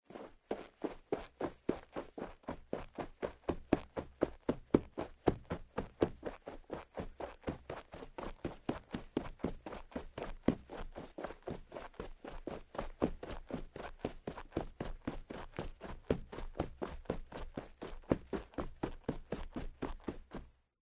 在薄薄的雪地上越来越快的奔跑－YS070525.mp3
通用动作/01人物/01移动状态/02雪地/在薄薄的雪地上越来越快的奔跑－YS070525.mp3
• 声道 立體聲 (2ch)